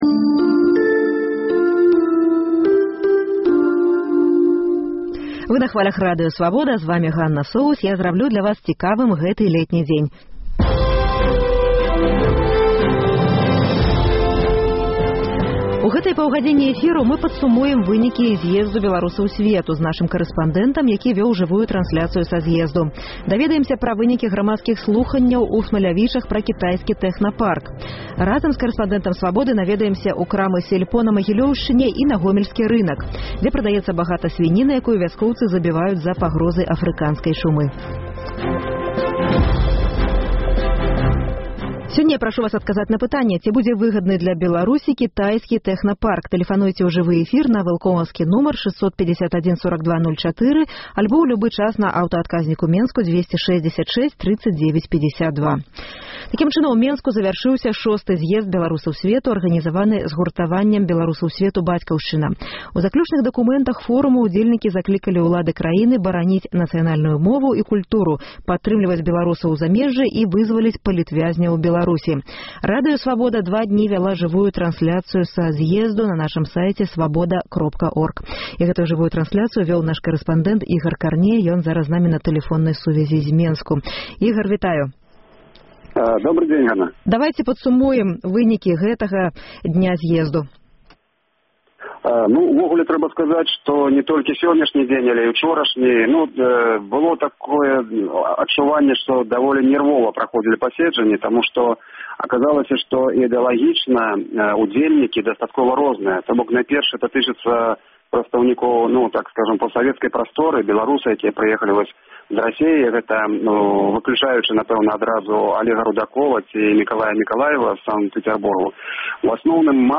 Вас чакаюць жывыя ўключэньні карэспандэнтаў «Свабоды» з шостага зьезду беларусаў сьвету і грамадзкіх слуханьняў пра кітайскі тэхнапарк са Смалявічаў То Чарнобыль, то сьвіная чума — рэпартаж з гомельскага рынку. Дасьледаваньне пра беларускае «сяльпо». Паўмільёна даляраў хабару для беларускага чыноўніка.